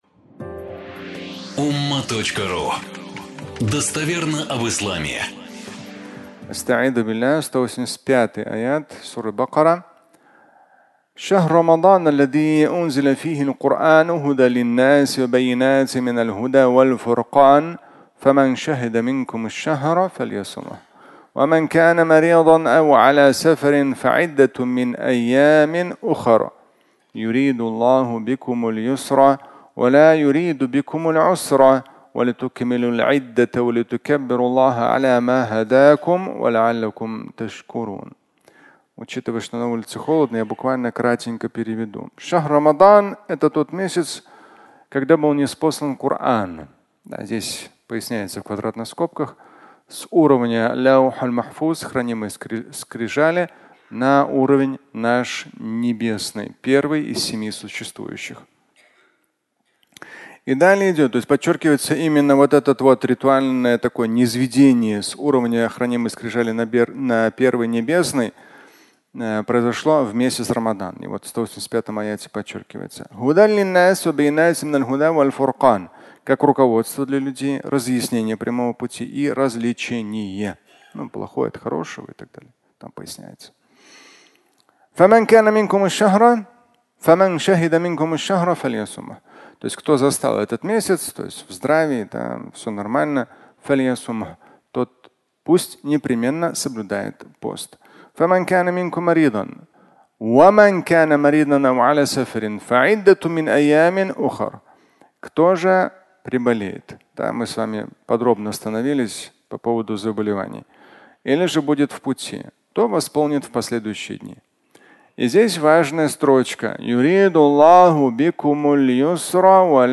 Легкость поста (аудиолекция)
Фрагмент пятничной лекции